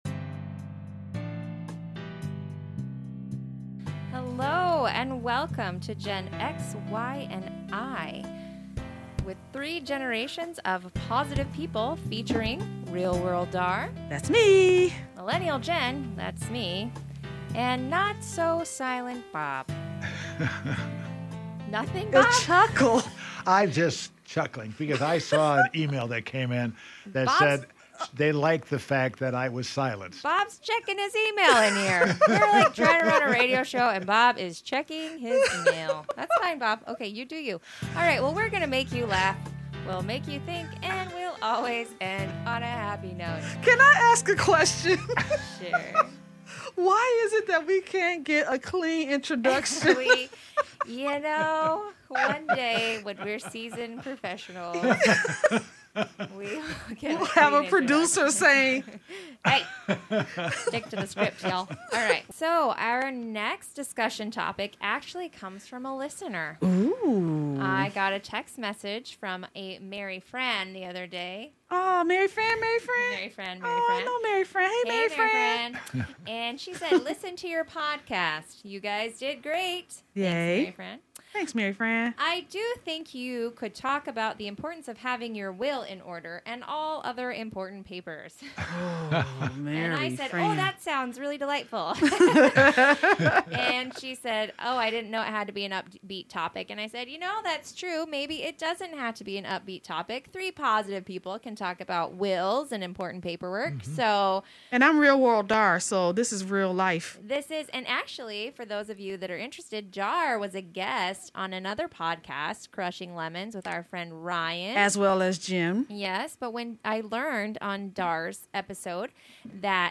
Listen to find out how this discussion of end of life matters became an informative yet upbeat and really funny episode.